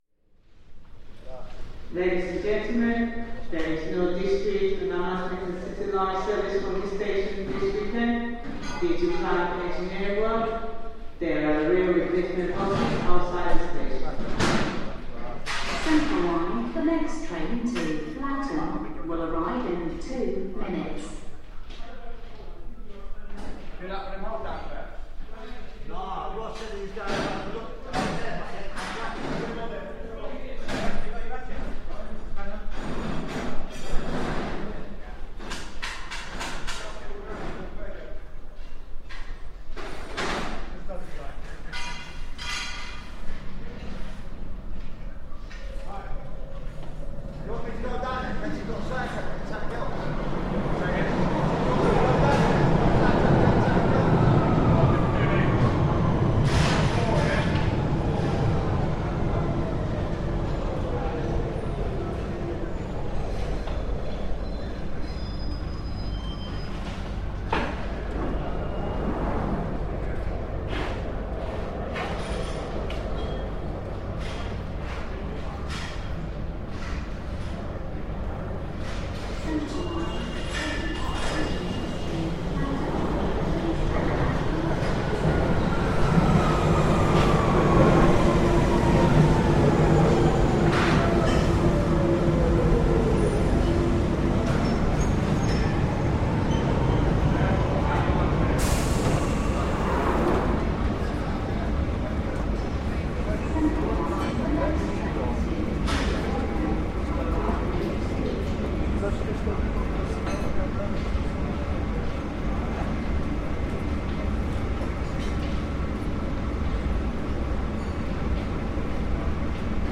Mile End station track works
Field recording from the London Underground by London Sound Survey.